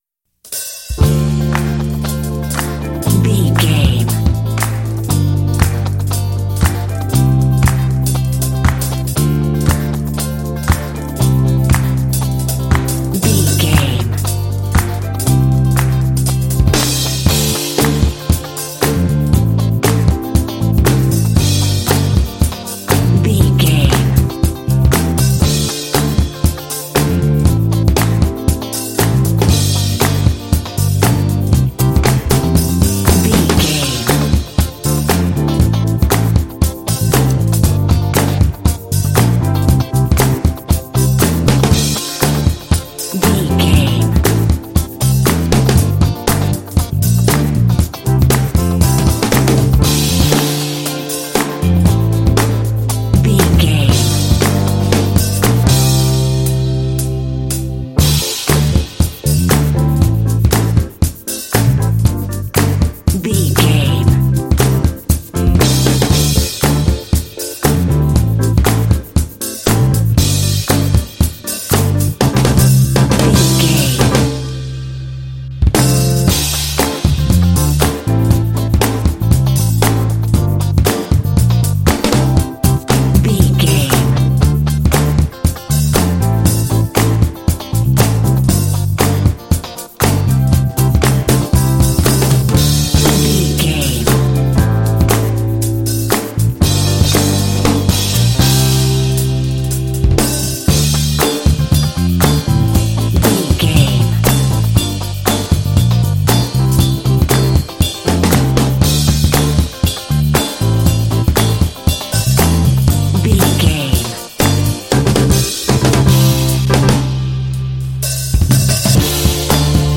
Uplifting
Aeolian/Minor
D
cool
smooth
fun
drums
electric guitar
bass guitar
synthesiser
brass
rock
alternative rock
indie